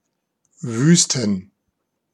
Ääntäminen
Ääntäminen Tuntematon aksentti: IPA: /ˈvyːstǝn/ Haettu sana löytyi näillä lähdekielillä: saksa Käännöksiä ei löytynyt valitulle kohdekielelle. Wüsten on sanan Wüste monikko.